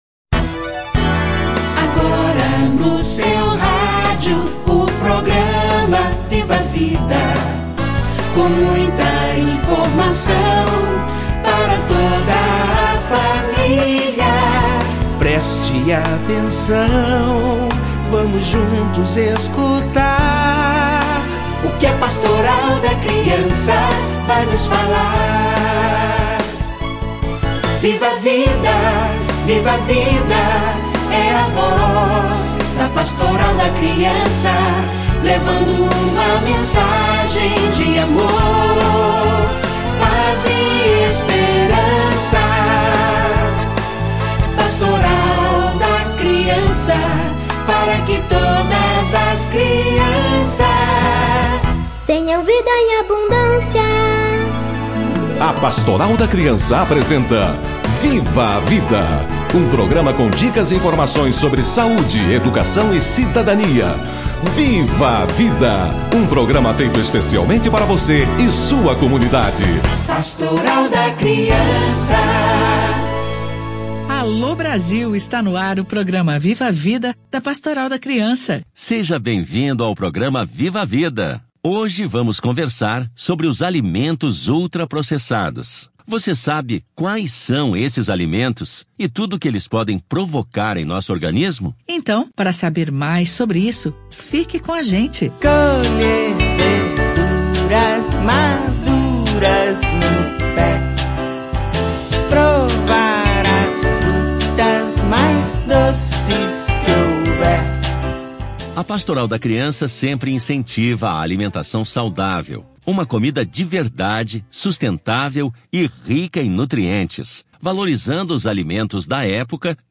- Programa de rádio Viva a Vida